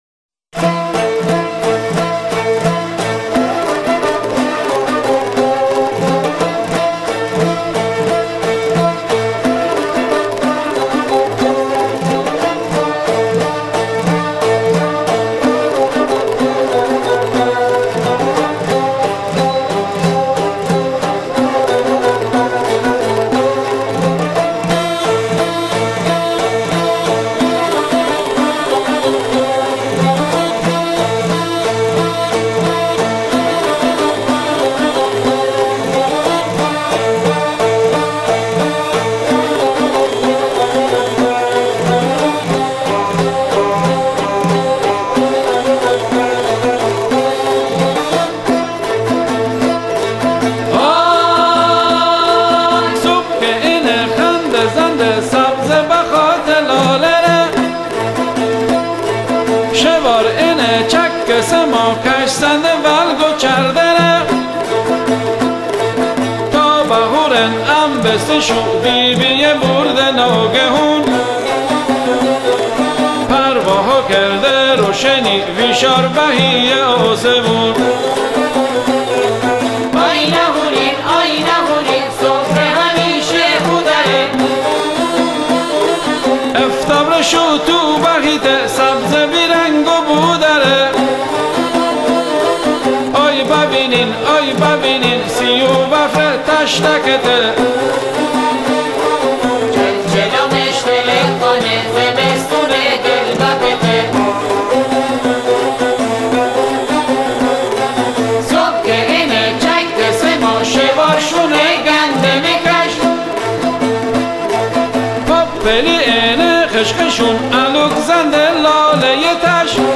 موسیقی مازندرانی